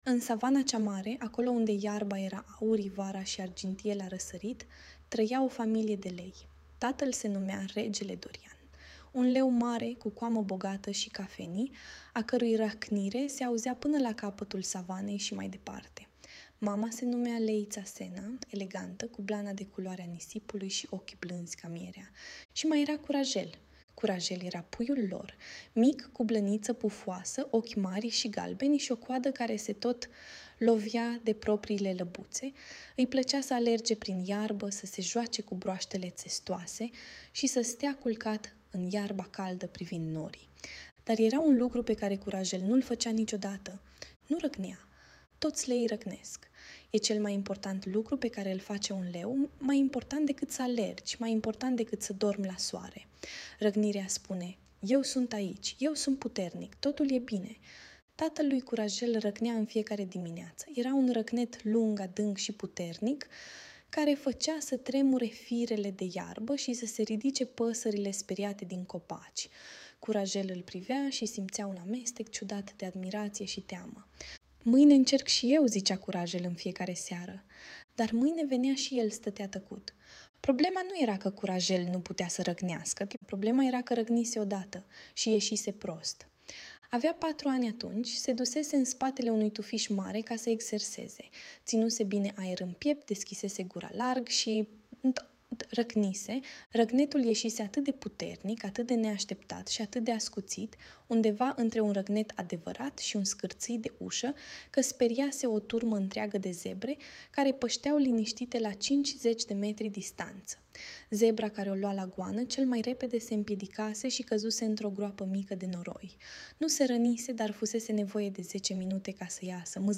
Audiobook Leul Curajel care nu voia să răcnească